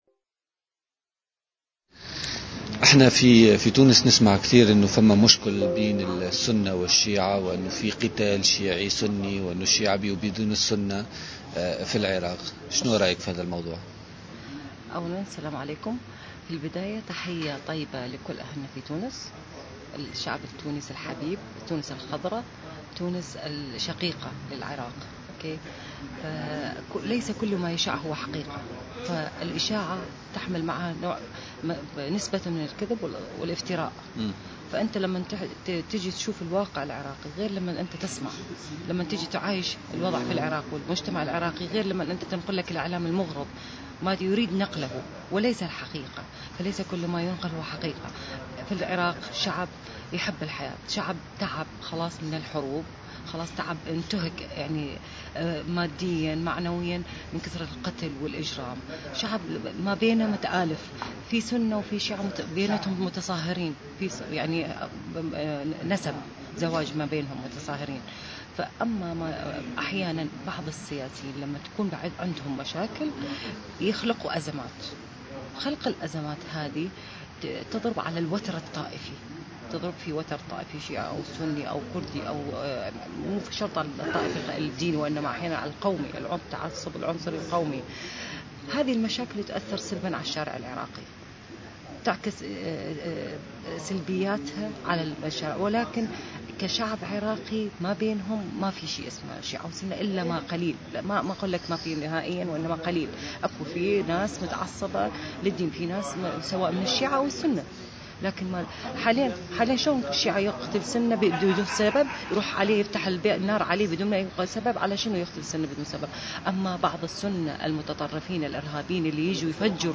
أكد عدد من المواطنين العراقيين